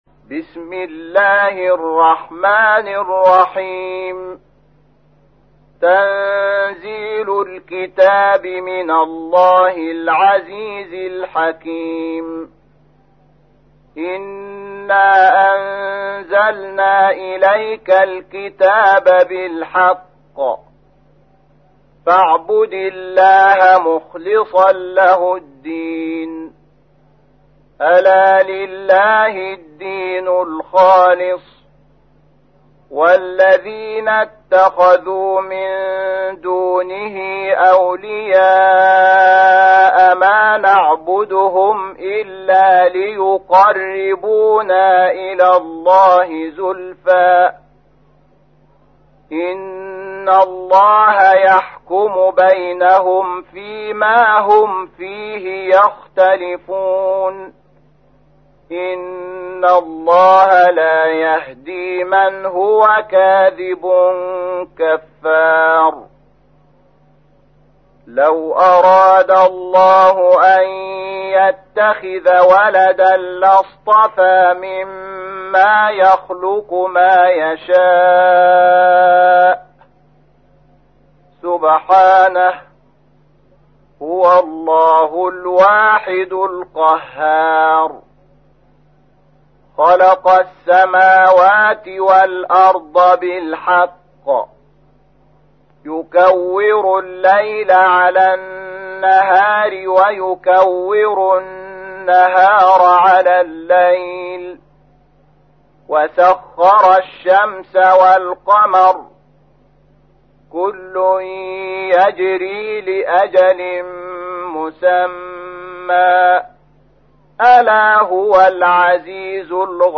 تحميل : 39. سورة الزمر / القارئ شحات محمد انور / القرآن الكريم / موقع يا حسين